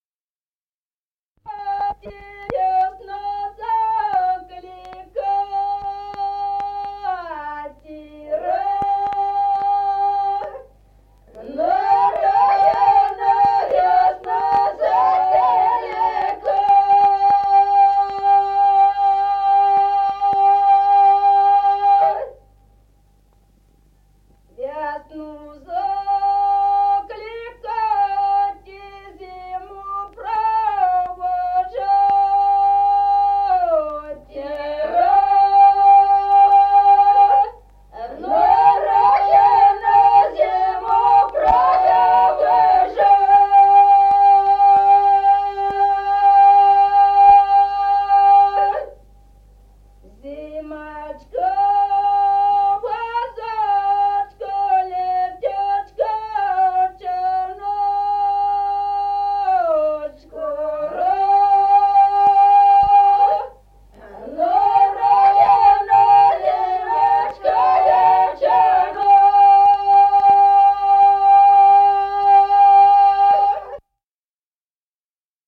Народные песни Стародубского района «Благослови, мати», весняная девичья.
1953 г., с. Остроглядово.